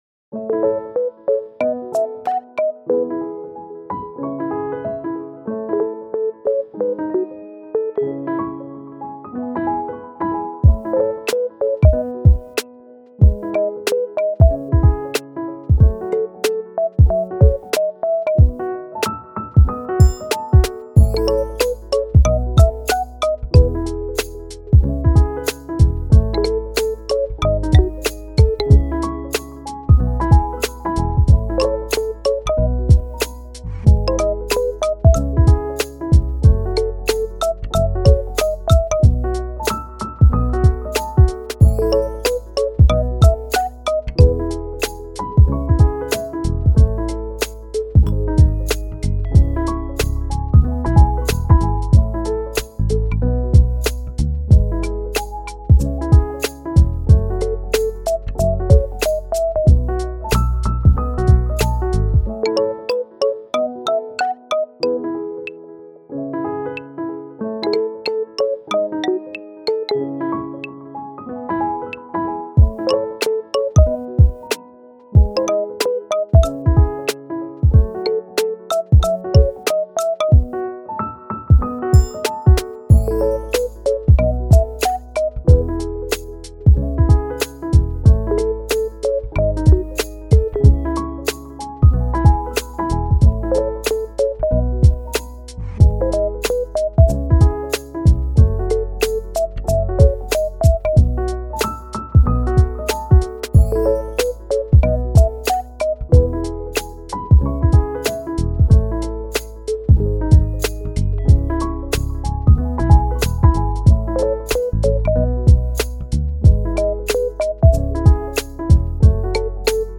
チル・穏やか
明るい・ポップ